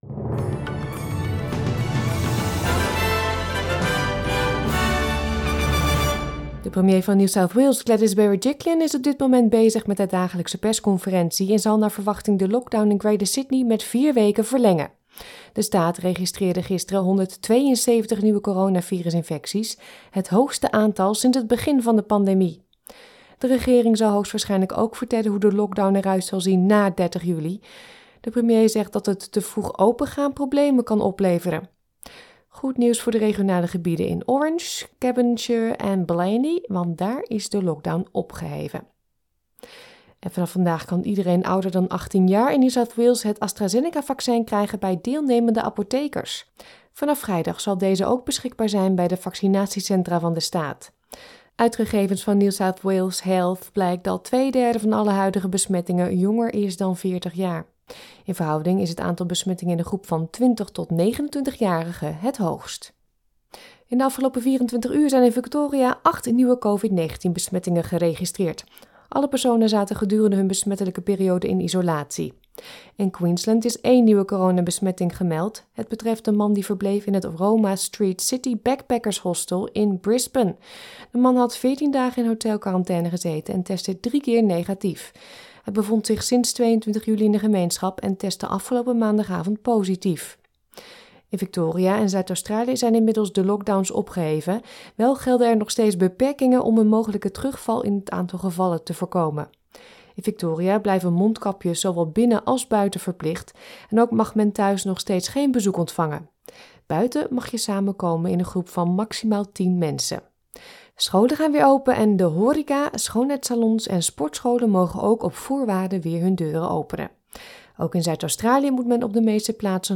Nederlands/Australisch SBS Dutch nieuwsbulletin van woensdag 28 juli 2021